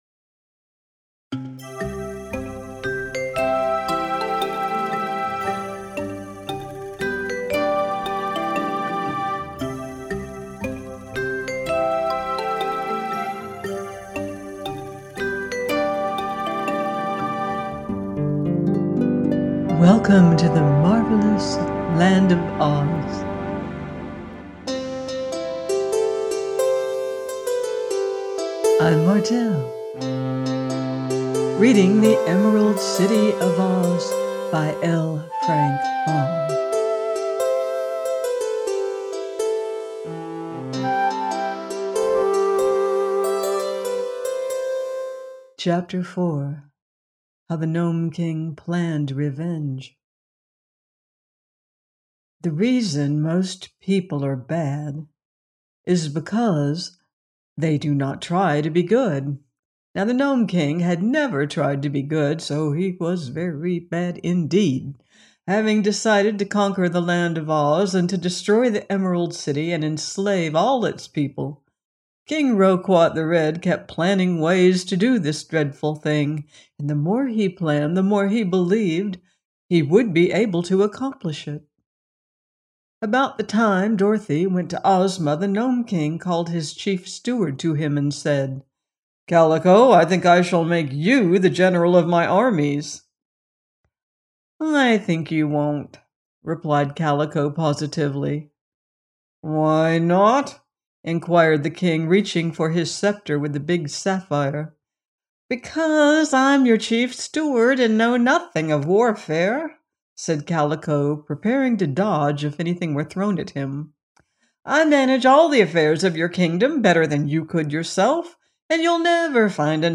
THE EMERALD CITY OF OZ – by L. Frank Baum - audiobook